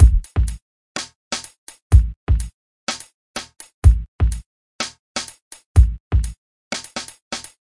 每天弗利 " 胸口怦怦跳
描述：一个男子气概的胸部重击。用Zoom H4N＆amp;记录用RX去噪。
标签： 捶击 耳光 胸部 击败
声道立体声